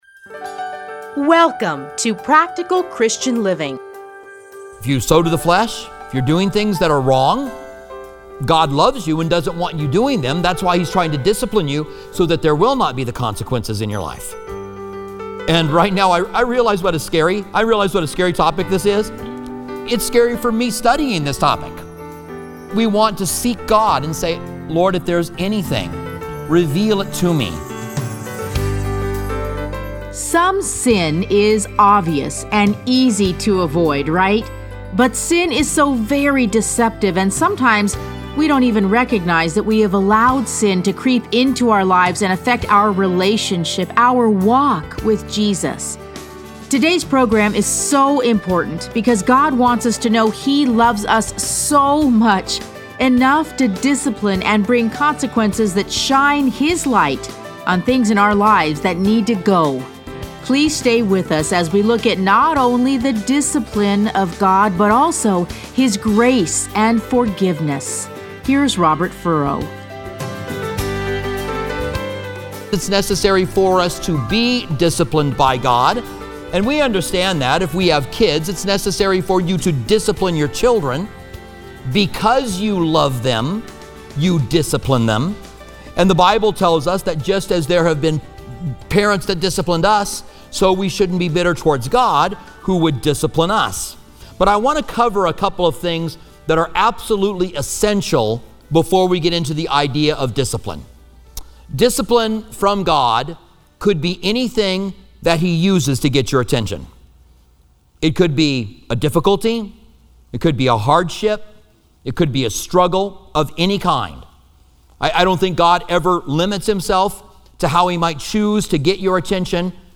Listen to a teaching from Hebrews 12:3-11.